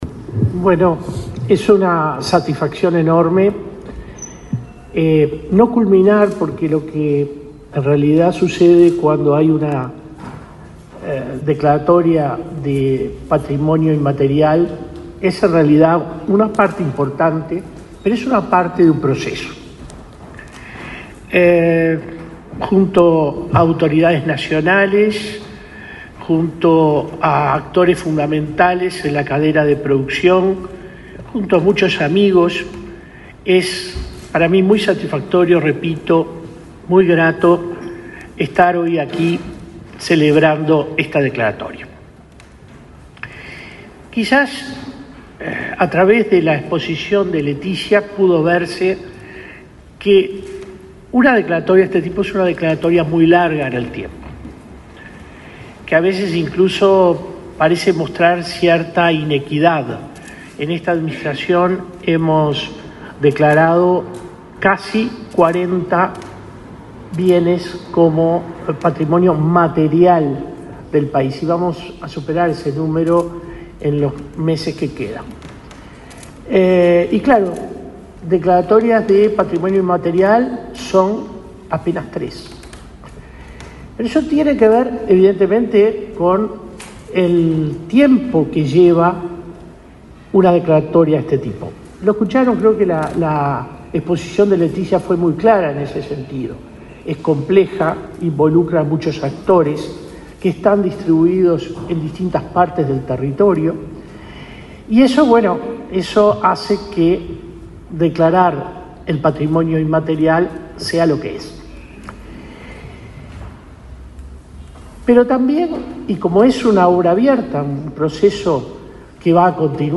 Palabras de autoridades en acto en el Palacio Legislativo
Palabras de autoridades en acto en el Palacio Legislativo 19/09/2024 Compartir Facebook X Copiar enlace WhatsApp LinkedIn Este jueves 19 en el Palacio Legislativo, el director de la Comisión del Patrimonio, William Rey, y la vicepresidenta de la República, Beatriz Argimón, se expresaron en el acto de declaratoria del Sistema Cultural de la Lana como Patrimonio Cultural Inmaterial del Uruguay.